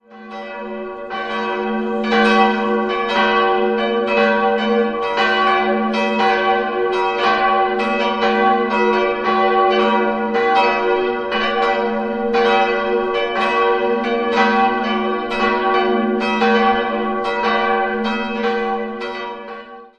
3-stimmiges G-Dur-Geläute: g'-h'-d'' Die große Glocke stammt von Gustav Schröck (Nürnberg) aus dem Jahr 1869, die mittlere von Karl Czudnochowsky aus dem Jahr 1959 und die kleine Glocke (ohne genaue Gießerangabe) entstand 1926.